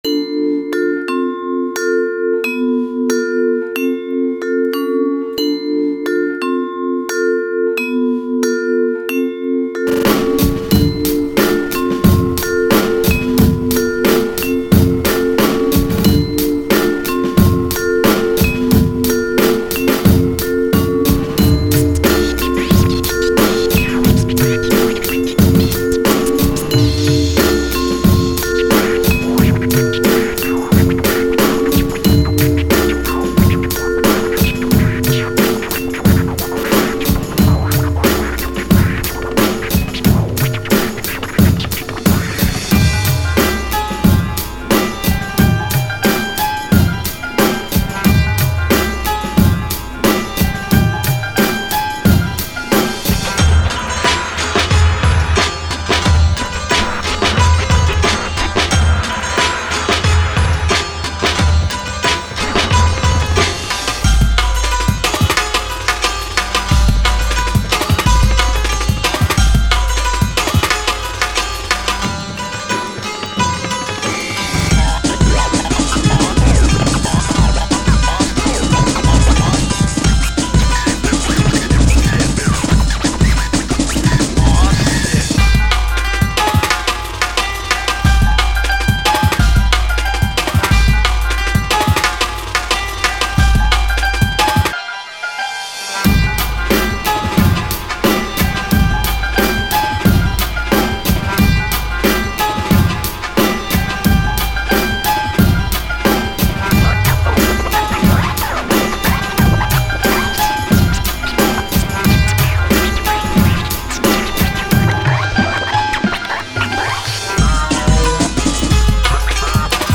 UNDERGROUND HIP HOP